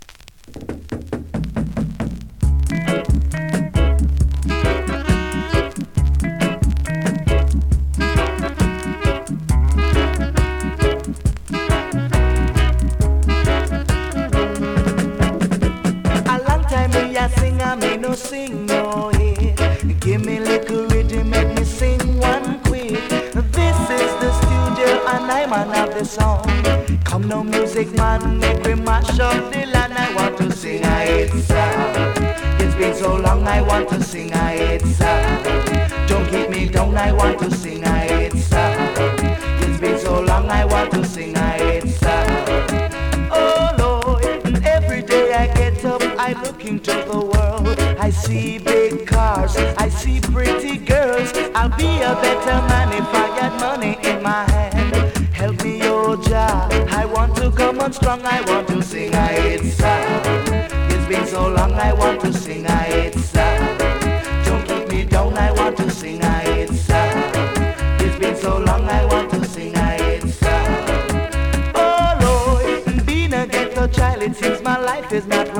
NEW IN!SKA〜REGGAE
スリキズ、ノイズ比較的少なめで